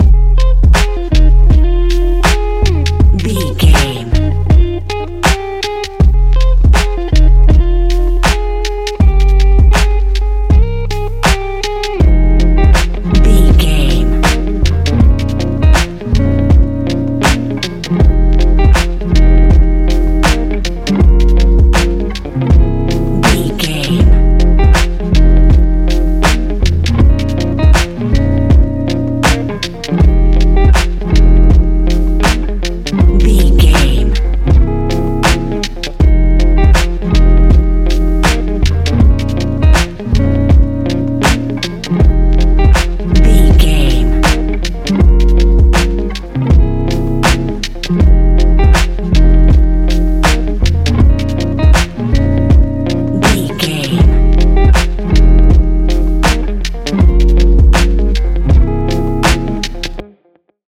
Ionian/Major
F♯
Lounge
sparse
chilled electronica
ambient